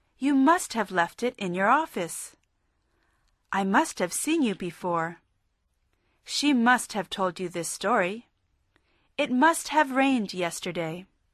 Este curso OM TALK de conversación fue desarrollado en inglés americano.